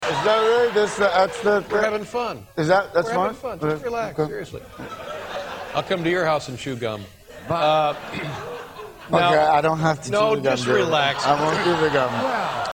Tags: Joaquin Phoenix Joaquin Phoenix on Drugs Joaquin Phoenix on David Letterman Joaquin Phoenix interview funny clip